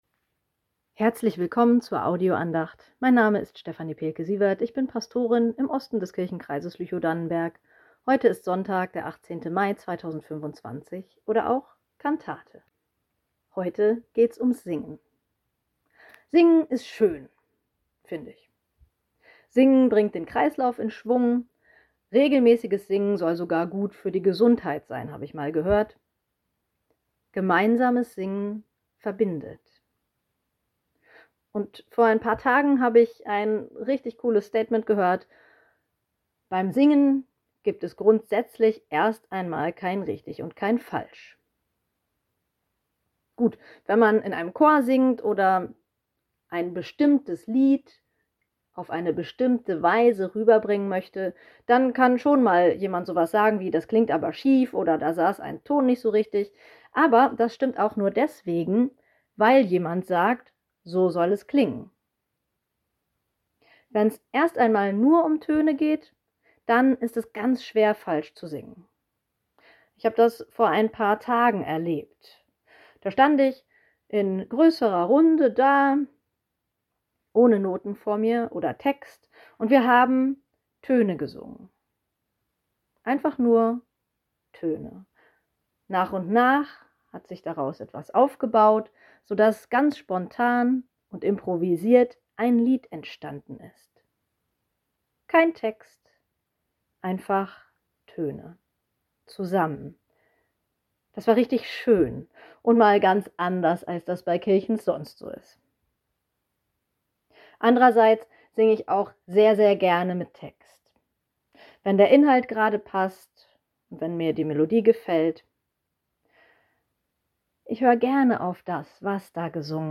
Seufzen und singen ~ Telefon-Andachten des ev.-luth. Kirchenkreises Lüchow-Dannenberg Podcast